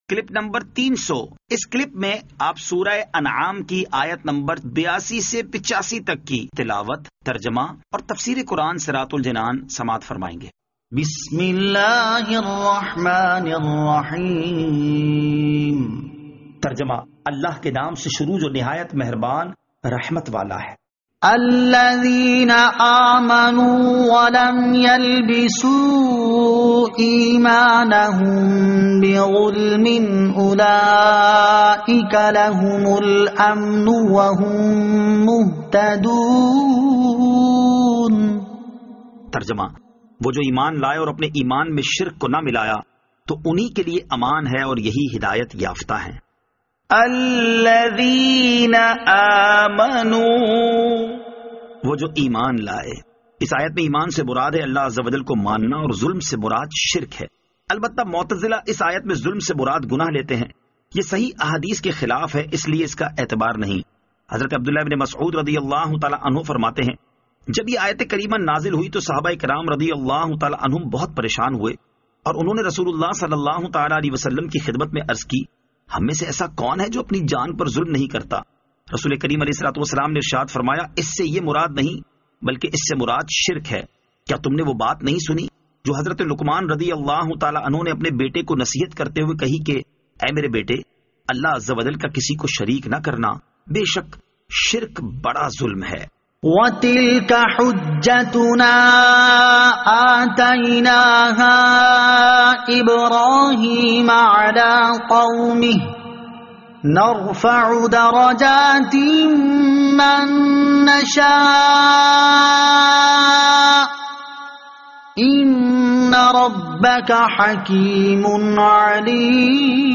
Surah Al-Anaam Ayat 82 To 85 Tilawat , Tarjama , Tafseer